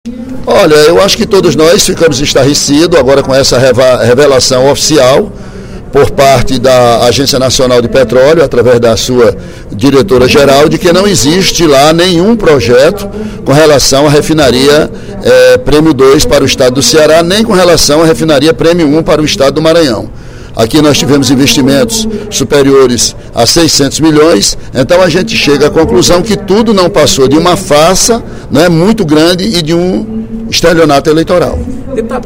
O deputado Ely Aguiar (PSDC) disse, em pronunciamento no primeiro expediente da sessão plenária da Assembleia Legislativa desta quinta-feira (12/03), que o povo cearense foi vítima da maior farsa política aplicada contra o Estado, que sempre acreditou nos governos Lula e Dilma.